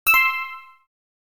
正确.mp3